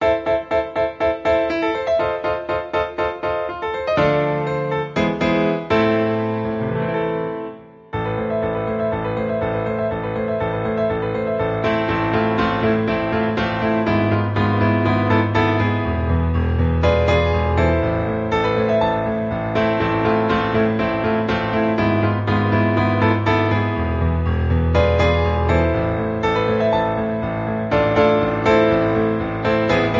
• Key: A Minor
• Instruments: Piano solo
• Genre: Pop, Disco